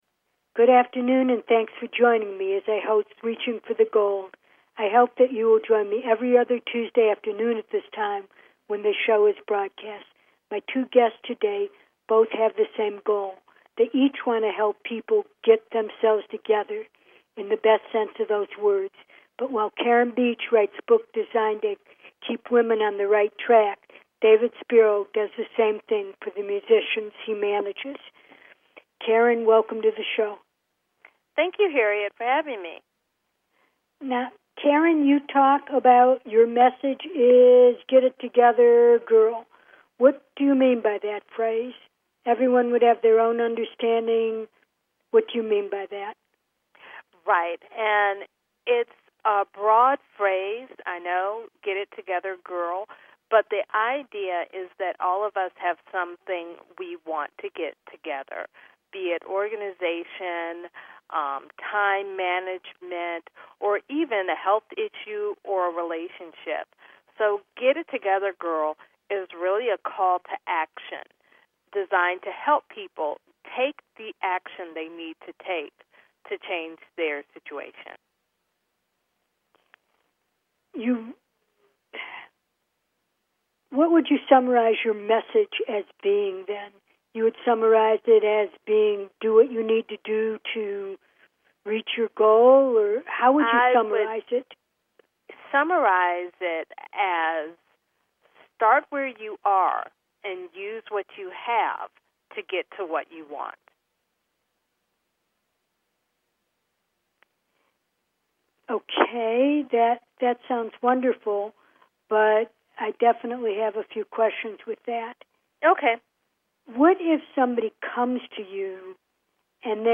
Talk Show Episode, Audio Podcast, Getting it Together and Doing it Your Way on , show guests , about Music World, categorized as Business,Health & Lifestyle,Music,Self Help